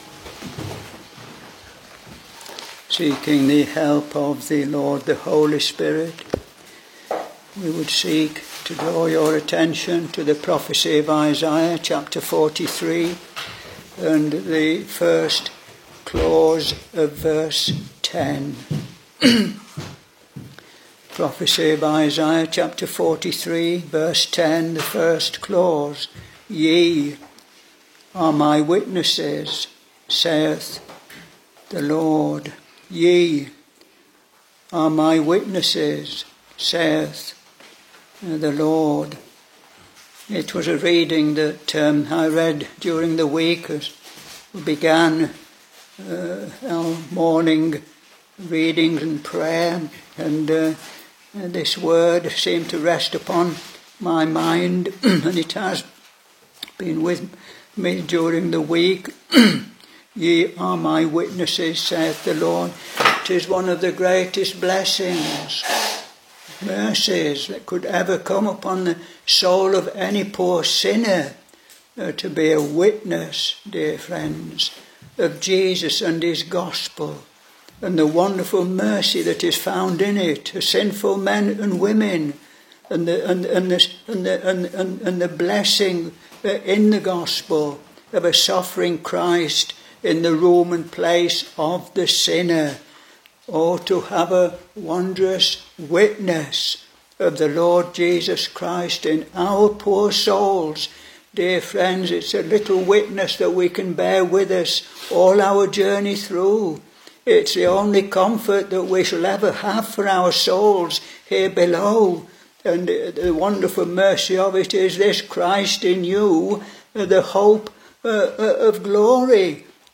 Sermons Isaiah Ch.43 v.10 (first clause in particular)